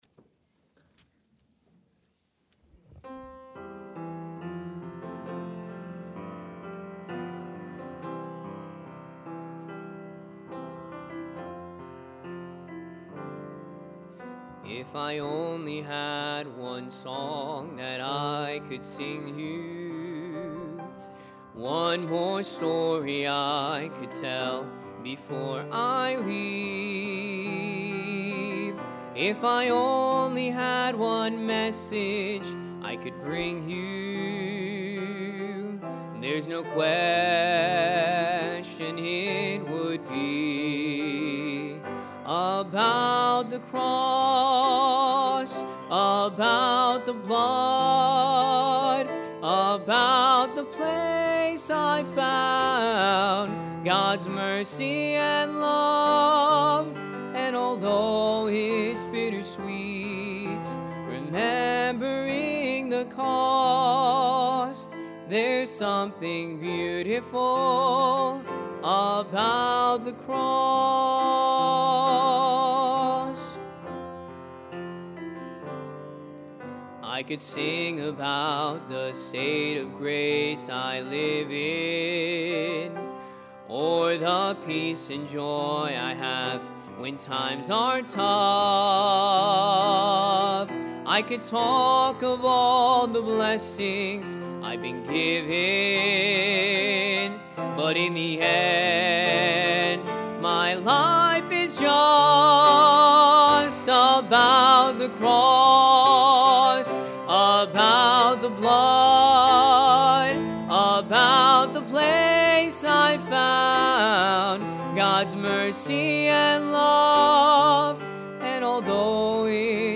Miracle of the Maniac | Sunday AM – Shasta Baptist Church